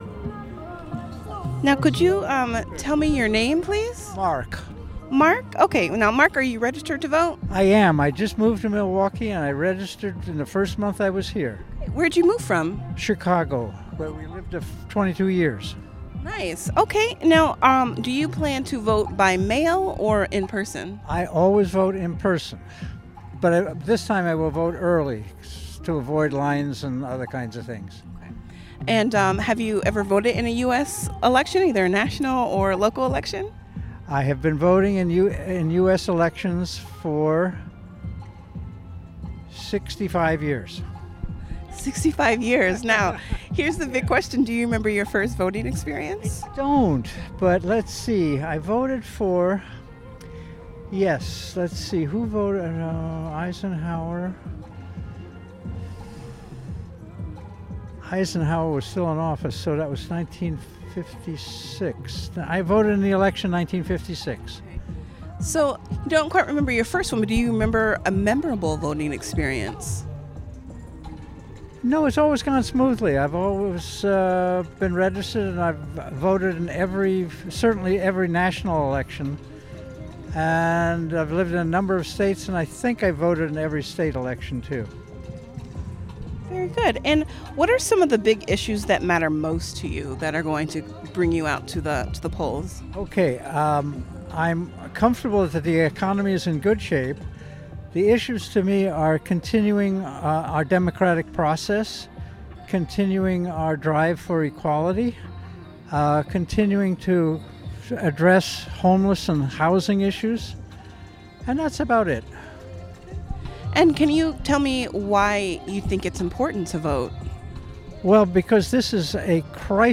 Location University of Wisconsin-Milwaukee